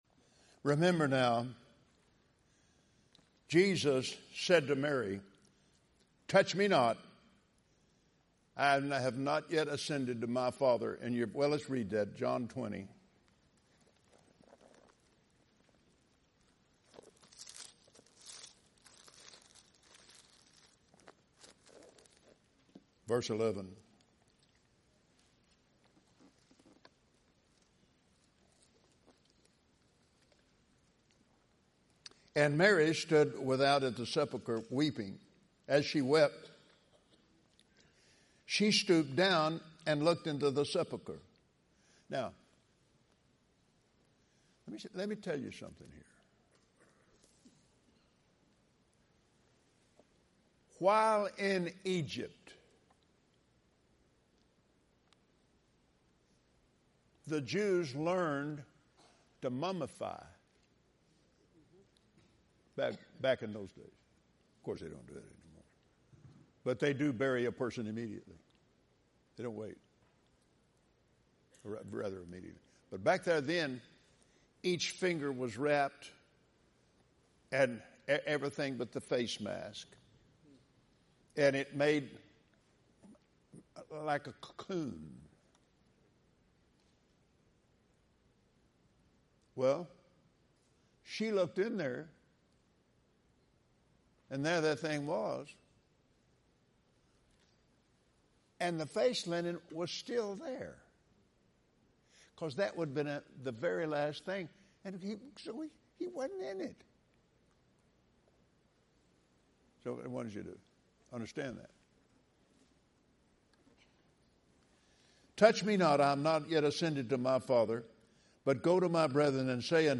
Branson Victory Campaign | Change the Environment With Christ in You - Kenneth Copeland | Fri., 7 p.m.
Listen as Kenneth Copeland teaches that Jesus cared so much for humanity, He allowed Himself to be crucified, died, was buried and went to hell for you. Then, Jesus rose again and defeated fear.